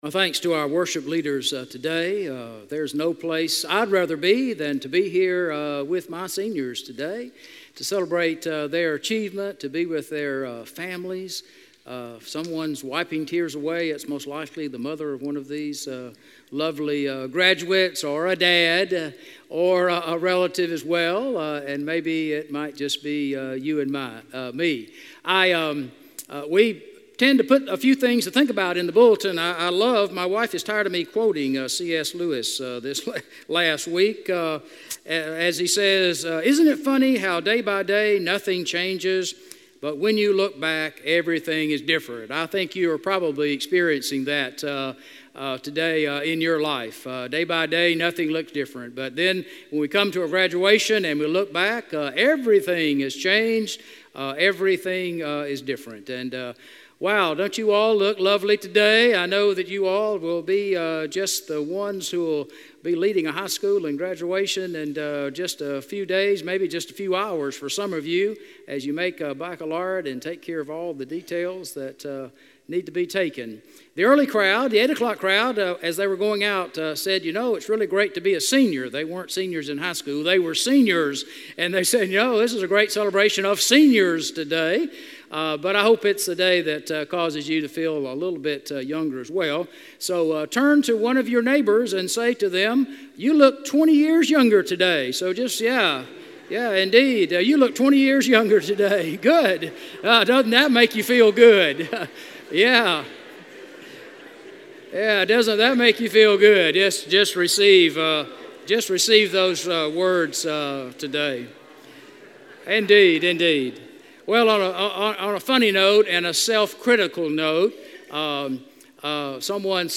Message: “Beside Yourself